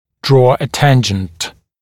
[drɔː ə ‘tænʤ(ə)nt][дро: э ‘тэндж(э)нт]провести касательную